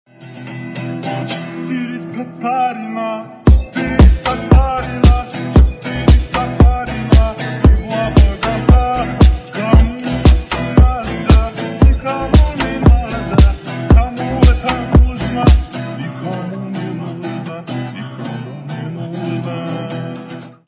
Громкие Рингтоны С Басами
Рингтоны Ремиксы » # Шансон Рингтоны